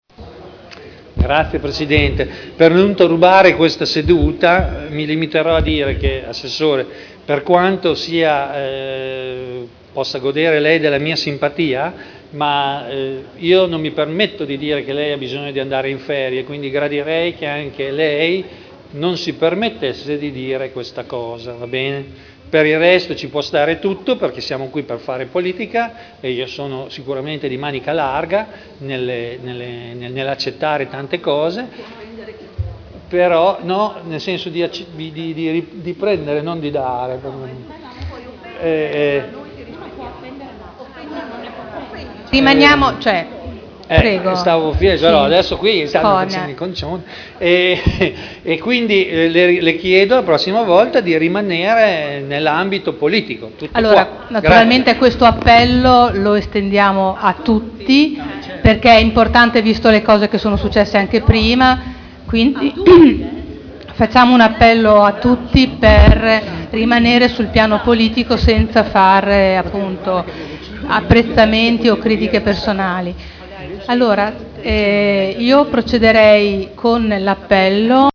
Seduta del 14/07/2011. Interviene per fatto personale.